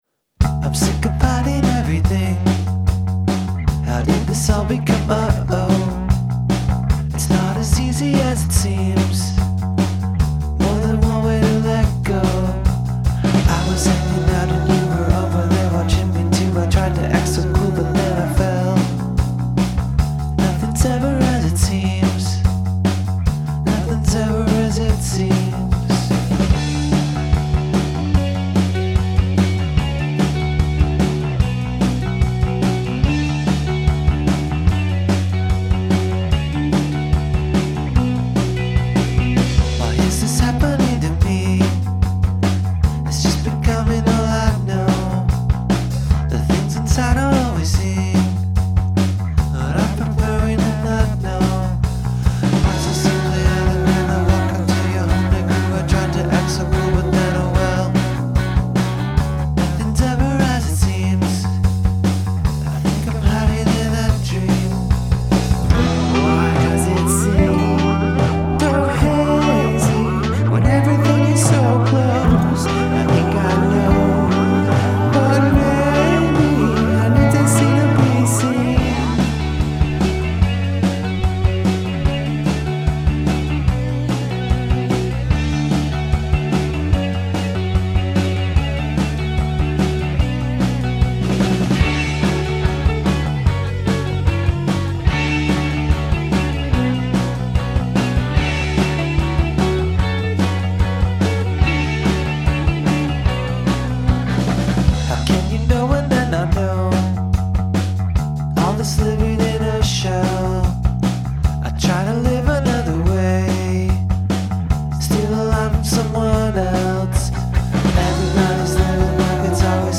Must include at least one mood shift (musical & lyrical)